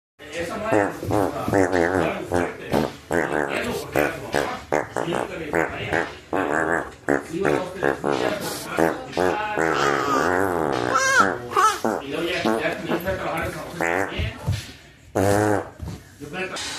Play and download DRINK FART sound effect.
DRINK FART